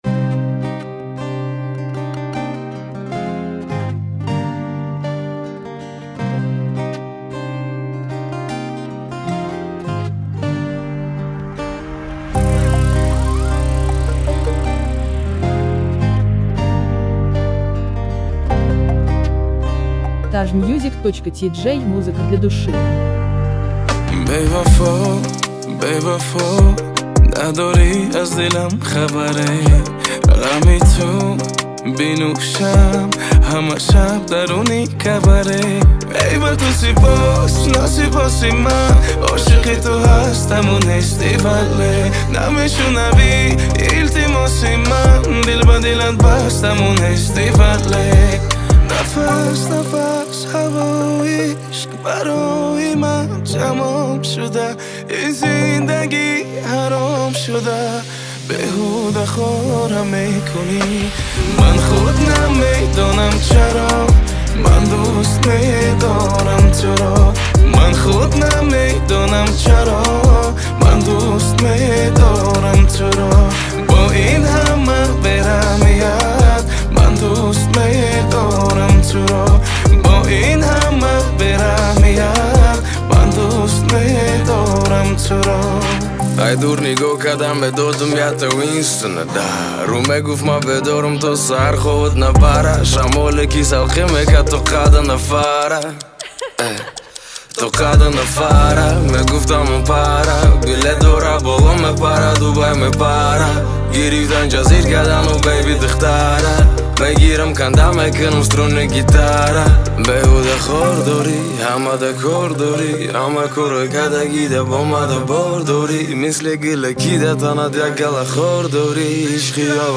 Музыка / 2024- Год / Таджикские / Клубная / Поп / Прочее